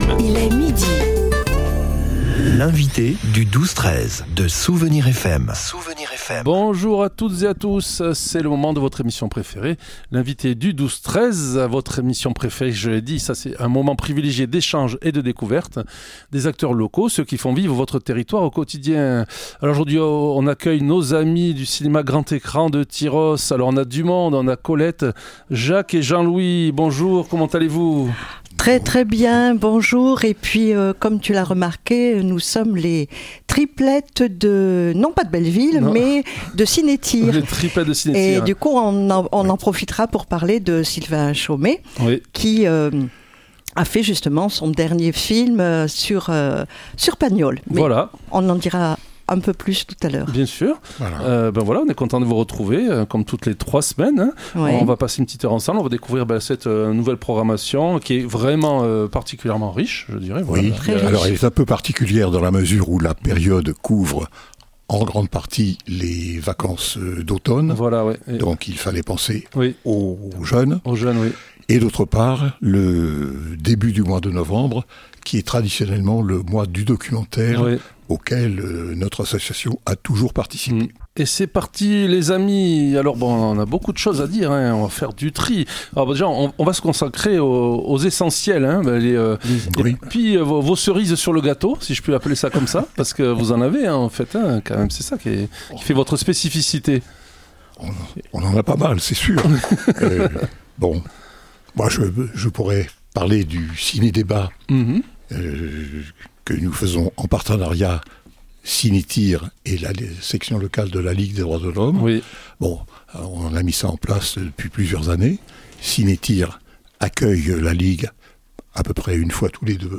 Aujourd'hui, nous avions l'équipe de nos habitués de Cinétyr au complet dans notre studio :